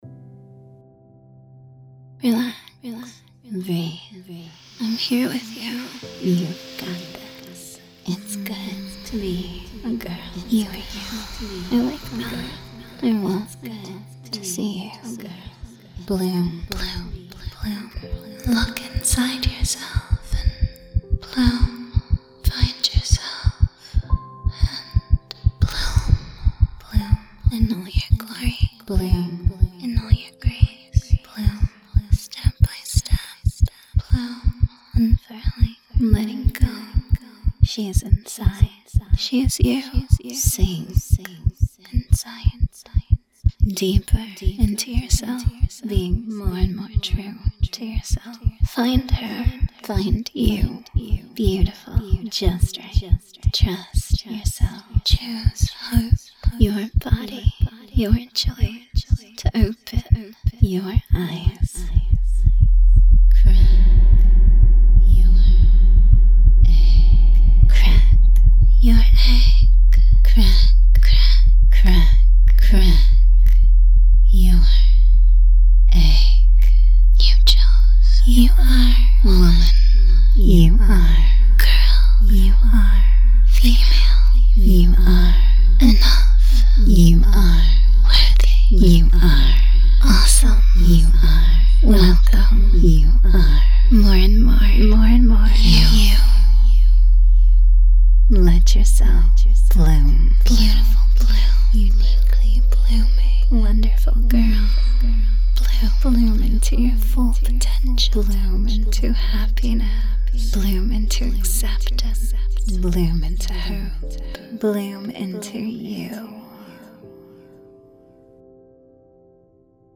Affectionate
Layers, SFX / Echoes, Reverb
Music
Be aware that if you have misophonic reactions to the sound of heartbeats, you should only listen at your own discretion.
The background music used here was purchased and licensed for use from pond5.